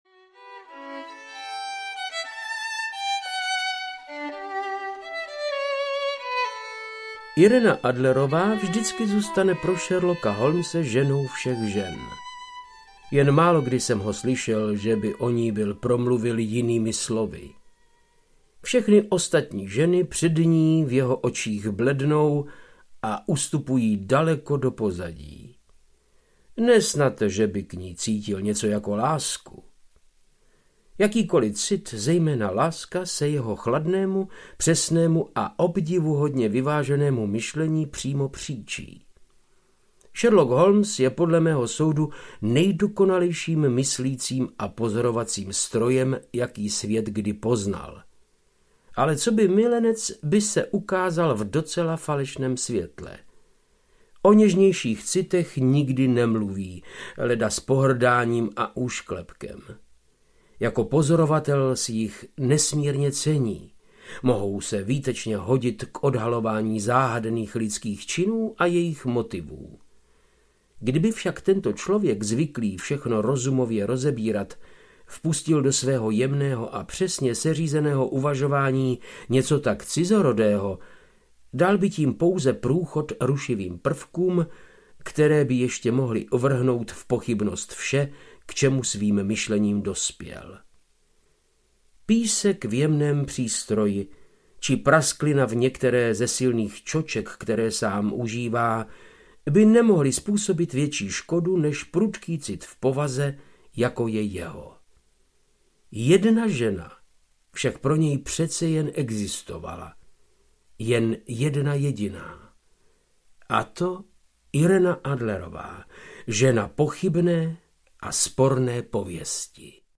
Ukázka z knihy
• InterpretVáclav Knop